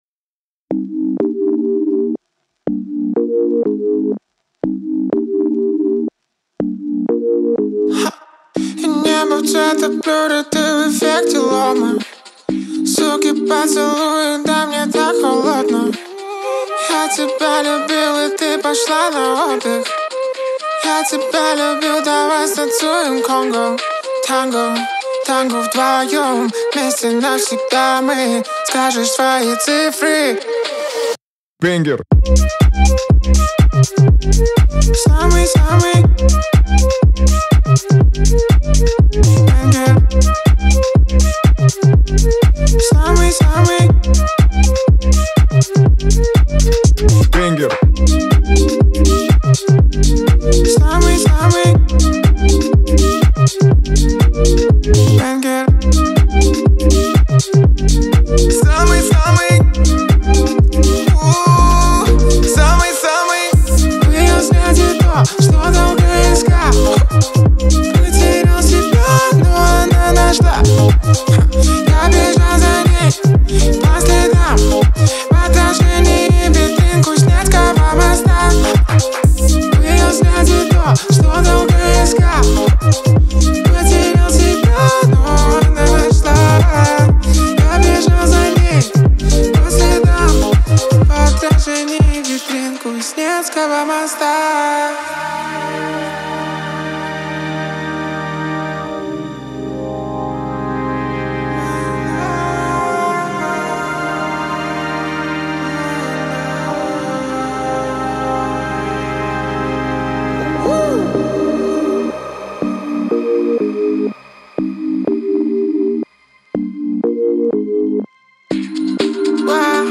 сочетая современный хип-хоп с элементами поп-музыки.